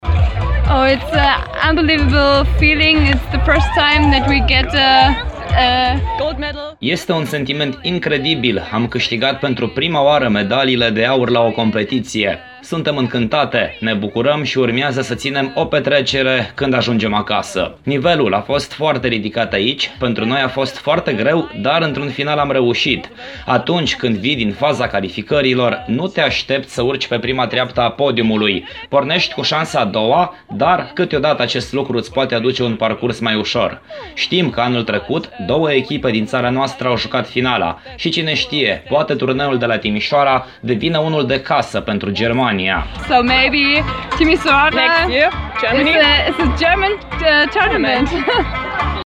Le ascultăm pe campanioanele CEV Satellite Timișoara 2016, care au câștigat în premieră un titlu.